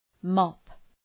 Προφορά
{mɒp}